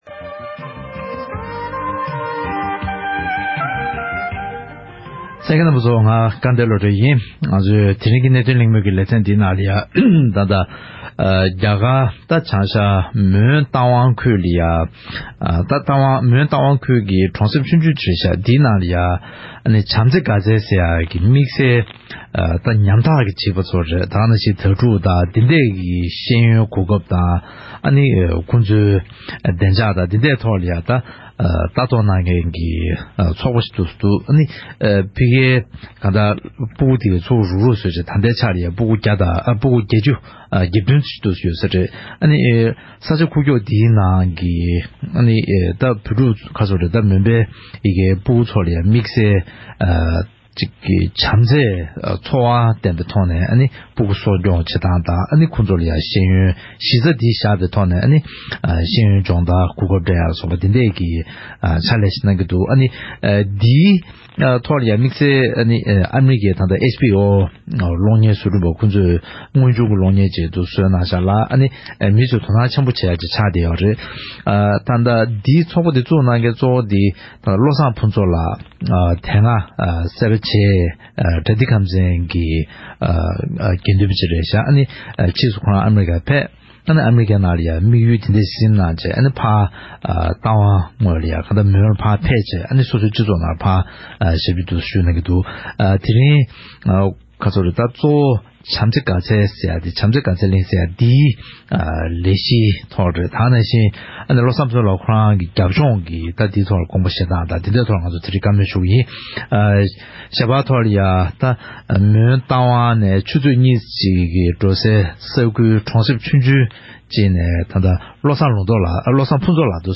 ༄༅། །ཐེངས་འདིའི་གནད་དོན་གླེང་མོལ་གྱི་ལེ་ཚན་ནང་།